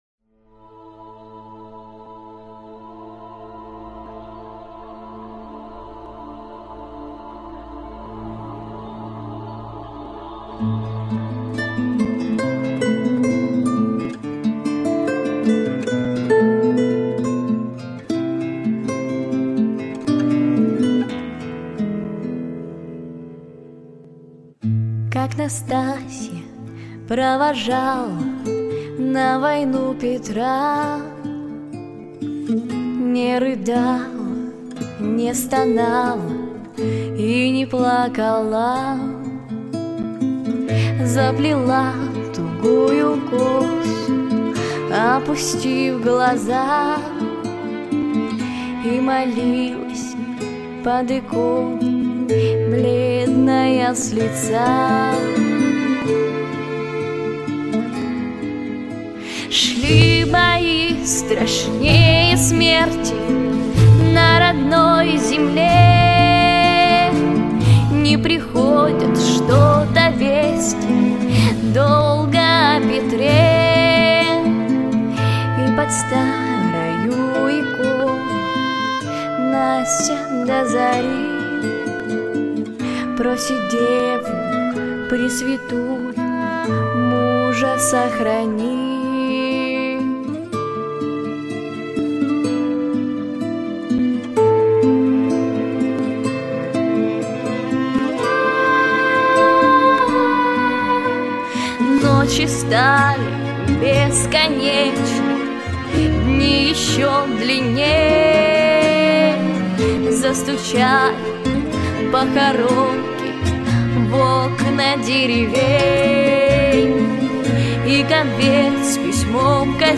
• Качество: Хорошее
• Категория: Детские песни
военные песни
Детская эстрадная вокальная студия